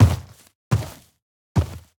Minecraft Version Minecraft Version snapshot Latest Release | Latest Snapshot snapshot / assets / minecraft / sounds / mob / sniffer / step5.ogg Compare With Compare With Latest Release | Latest Snapshot
step5.ogg